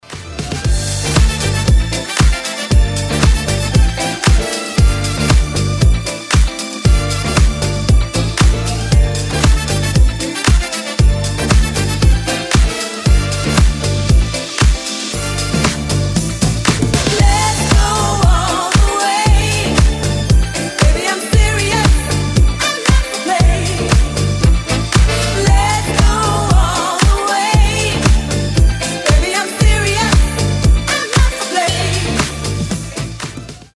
deep house
Звучание ретро в современной музыке